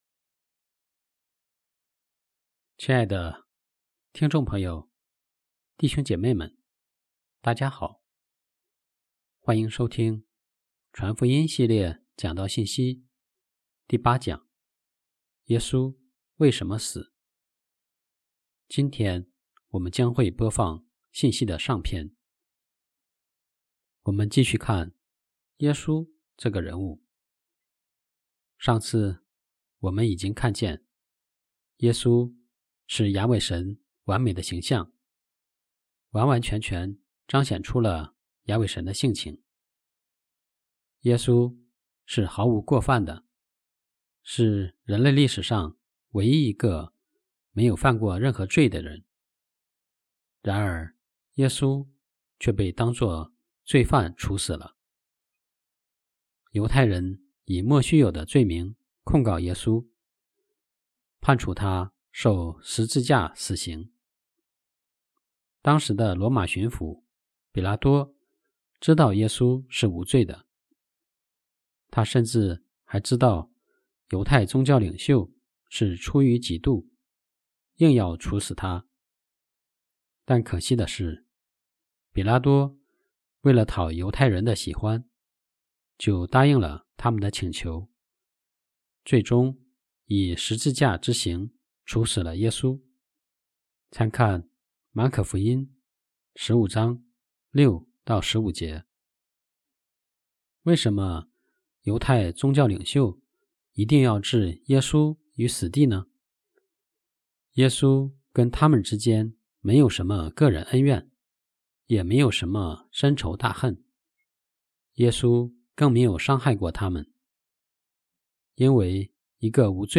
第8讲-耶稣为什么死（一）-.mp3